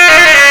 SAX.wav